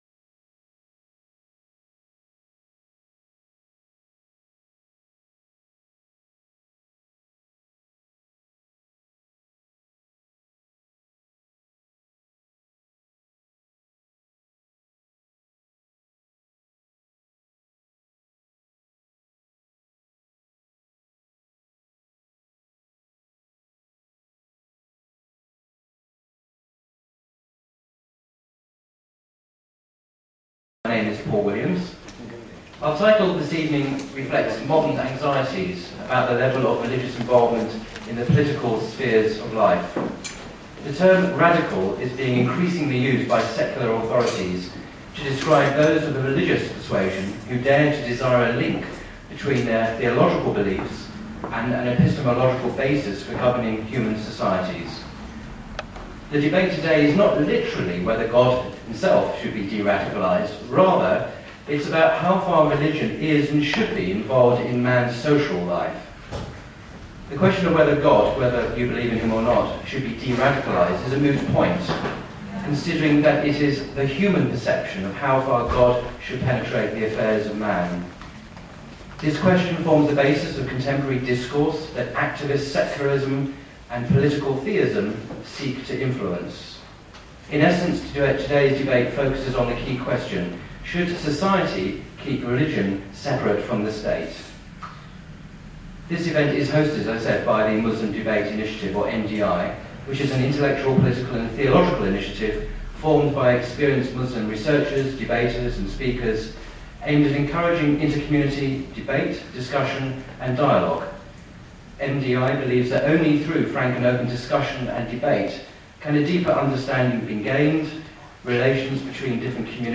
Atheist vs Muslim Debate - Secularism vs Political Theism (Part 1).mp3